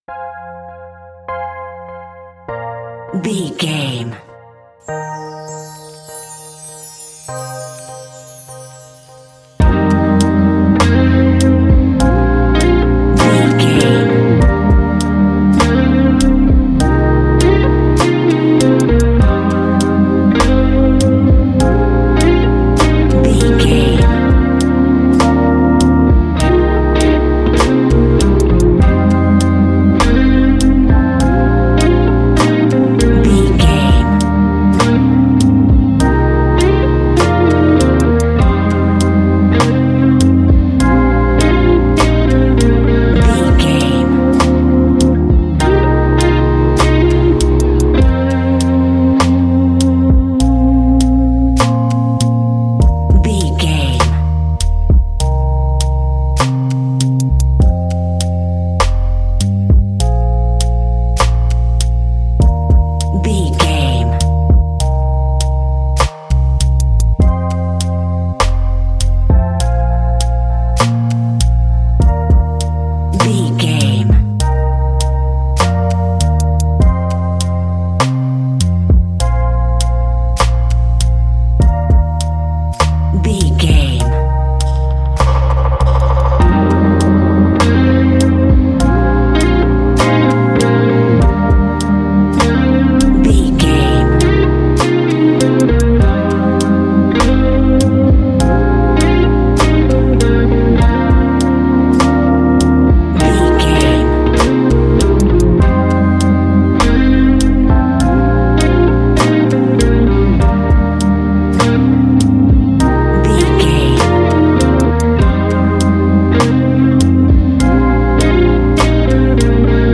Ionian/Major
A♯
laid back
Lounge
sparse
new age
chilled electronica
ambient
atmospheric